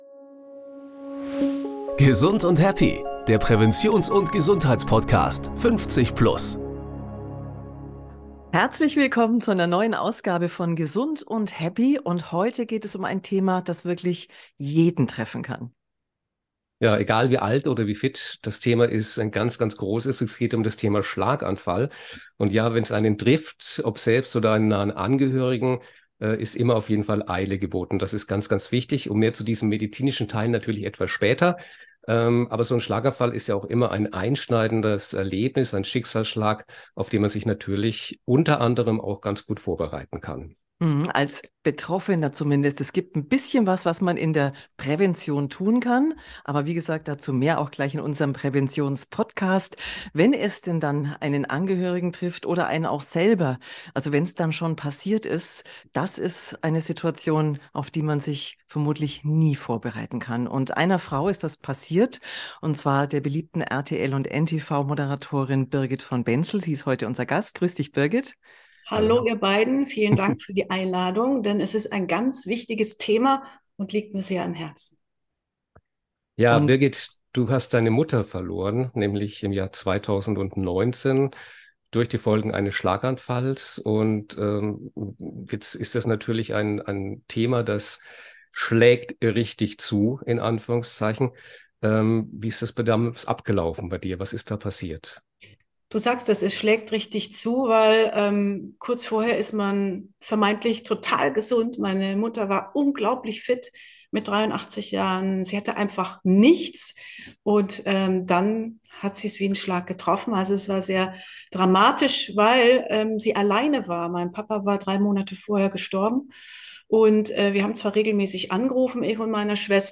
In einem emotionalen Gespräch